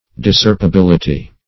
Search Result for " discerpibility" : The Collaborative International Dictionary of English v.0.48: Discerpibility \Dis*cerp`i*bil"i*ty\, Discerptibility \Dis*cerp`ti*bil"i*ty\, n. Capability or liableness to be discerped.